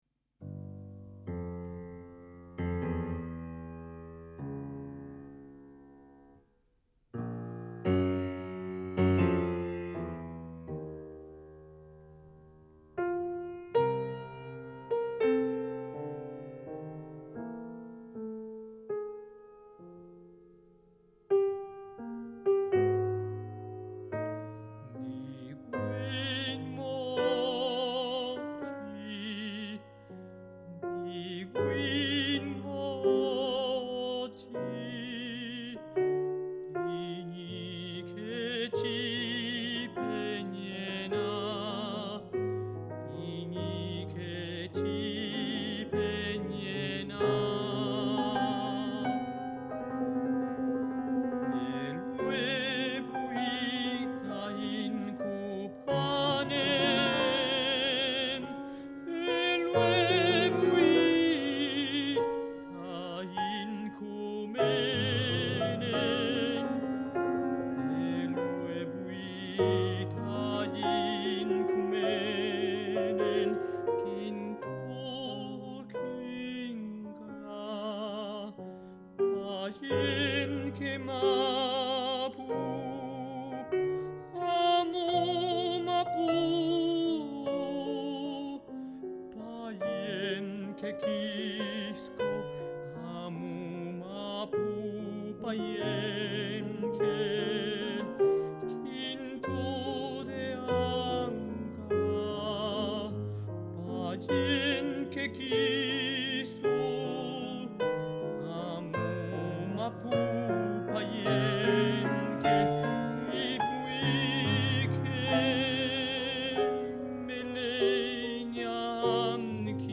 Canto
Música tradicional